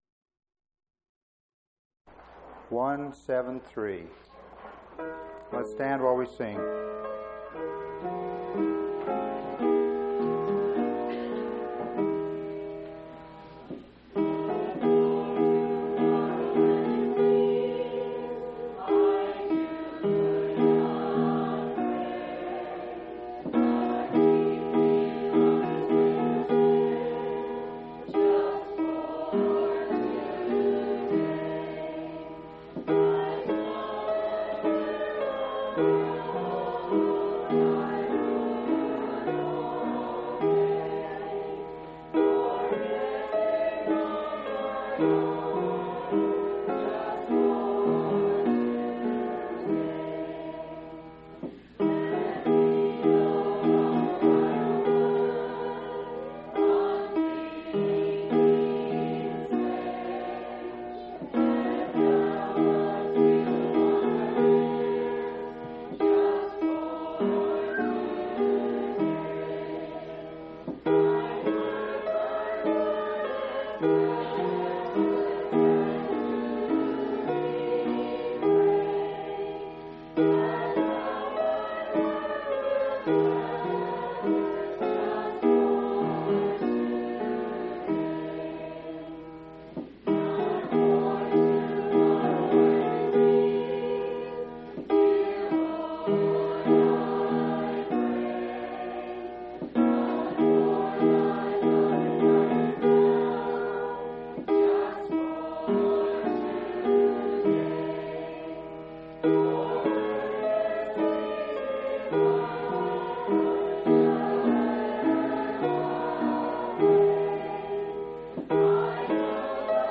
1/20/2002 Location: Phoenix Local Event
Sermon